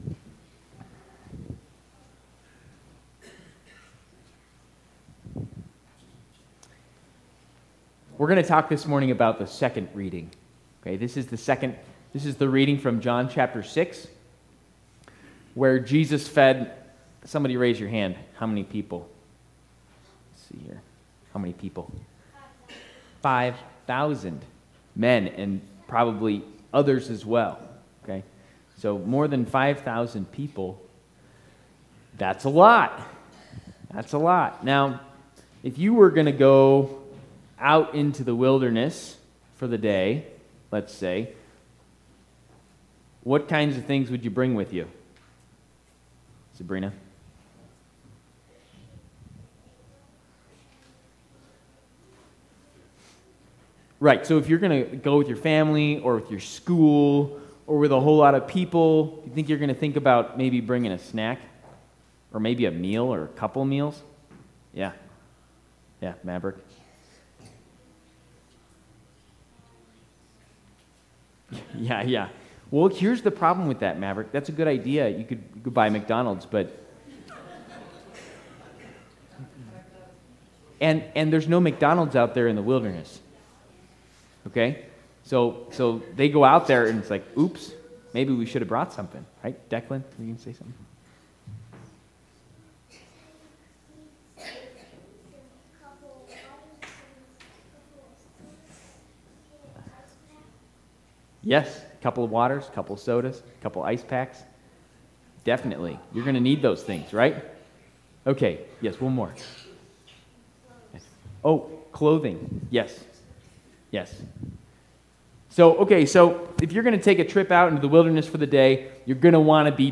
Sermon for TLS chapel on the fourth week of Lent  March 6